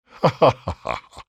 vs_falgerno_haha.wav